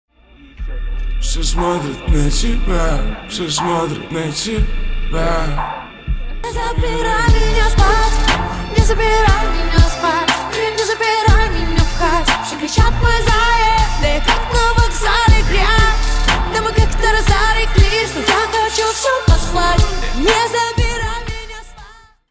• Качество: 320, Stereo
мужской вокал
женский вокал
Хип-хоп